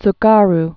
(ts-gär)